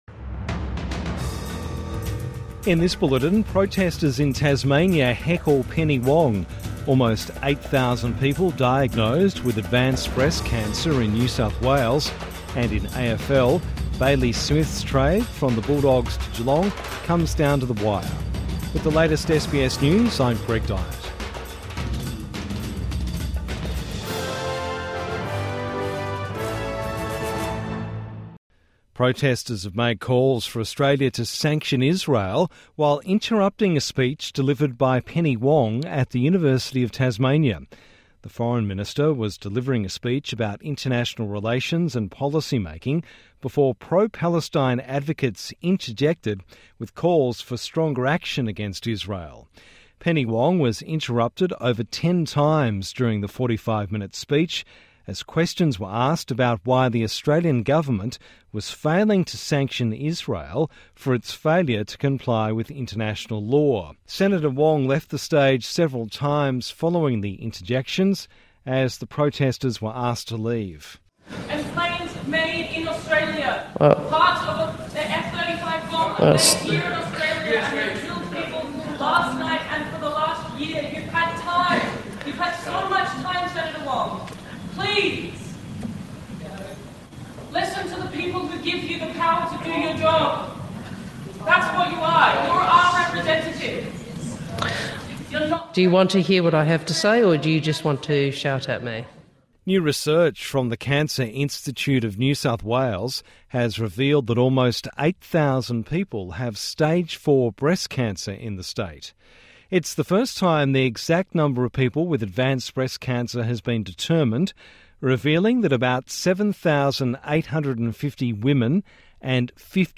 Evening News Bulletin 16 October 2024